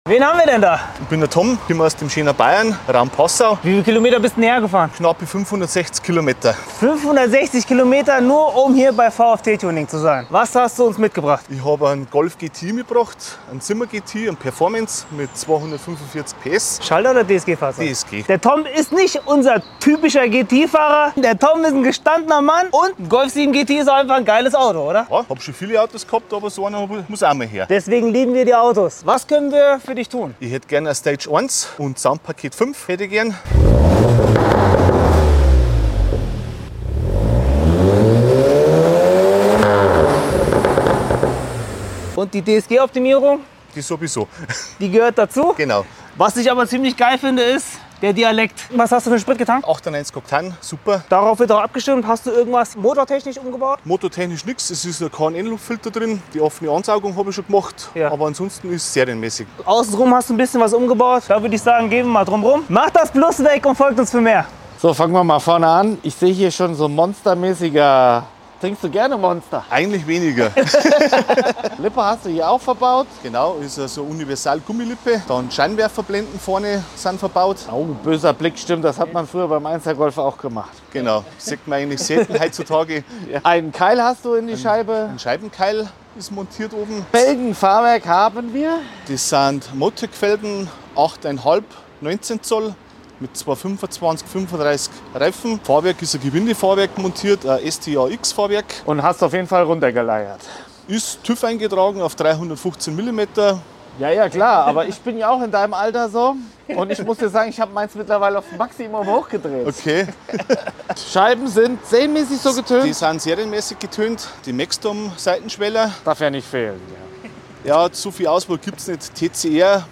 VW Golf 7 GTI FL sound effects free download
VW Golf 7 GTI FL Stage1 Tuning mit Soundcheck Soundpaket Dynorun Pops and Bangs